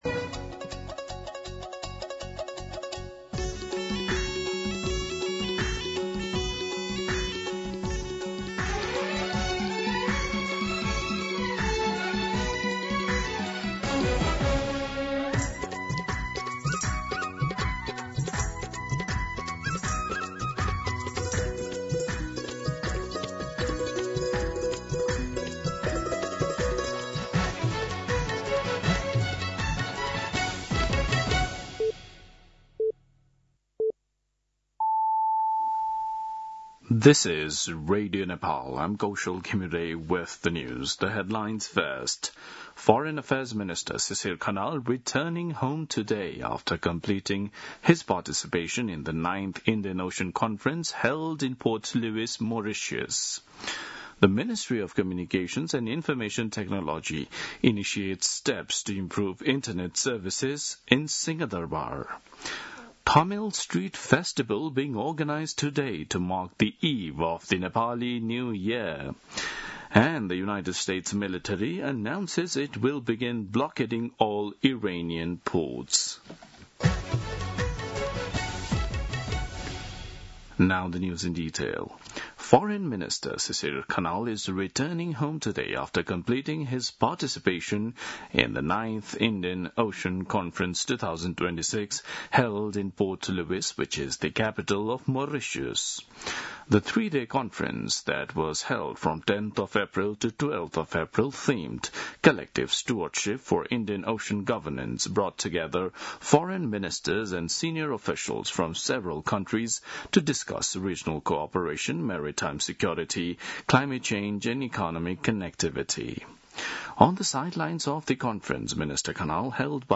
दिउँसो २ बजेको अङ्ग्रेजी समाचार : ३० चैत , २०८२
2pm-English-News-30.mp3